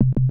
DistClickBlocked1.wav